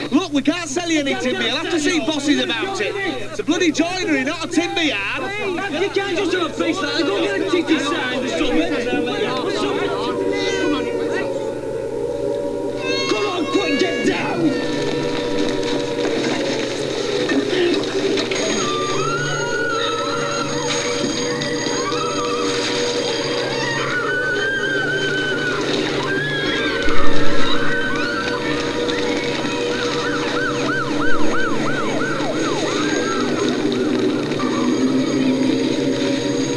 Some sound clips taken from the film.
A sound clip of the panic scenes, as the warning sirens sound over Sheffield.  It opens with Jimmy speaking, but moves on to show the scene in the crowded city centre, where people rush for cover, screaming and shouting.
NuclearPanic.wav